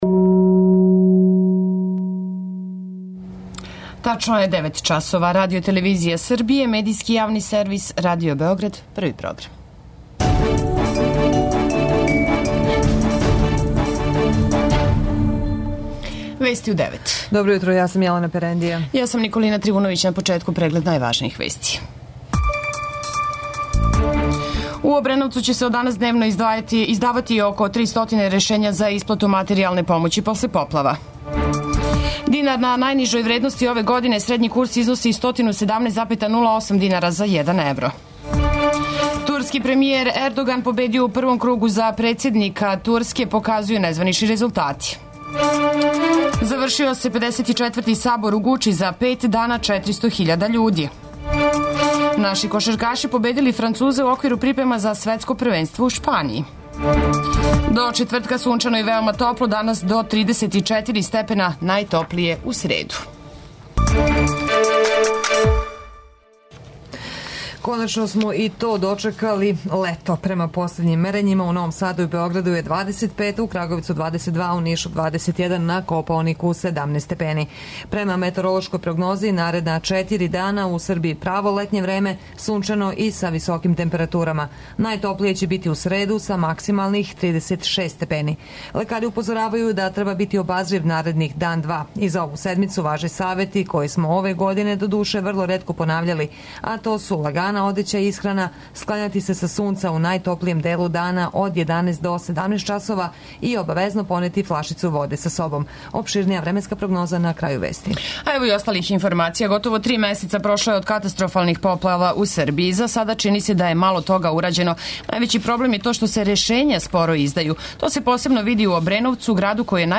преузми : 10.31 MB Вести у 9 Autor: разни аутори Преглед најважнијиx информација из земље из света.